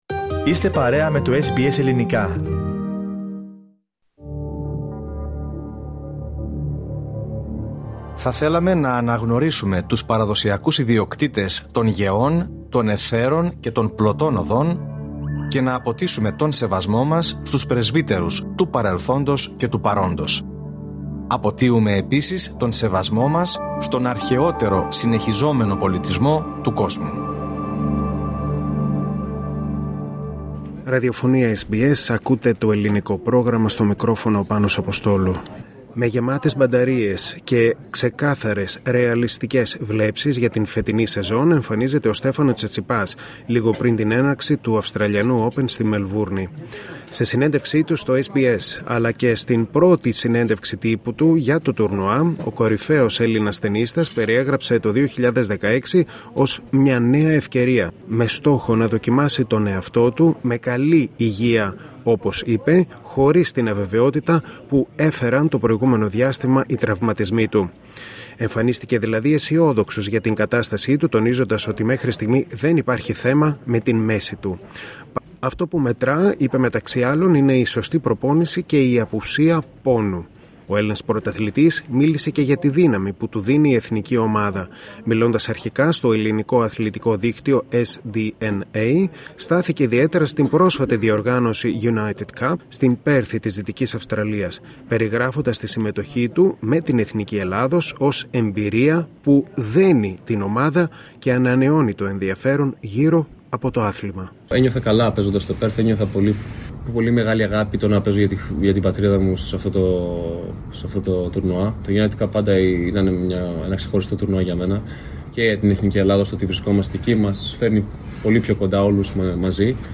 Σε συνέντευξή του στο SBS Greek, αλλά και στη πρώτη συνέντευξη Τύπου του για το τουρνουά, ο κορυφαίος Έλληνας τενίστας περιέγραψε το 2026 ως «μια νέα ευκαιρία», με στόχο να δοκιμάσει τον εαυτό του με υγεία και χωρίς την αβεβαιότητα που φέρνουν οι τραυματισμοί.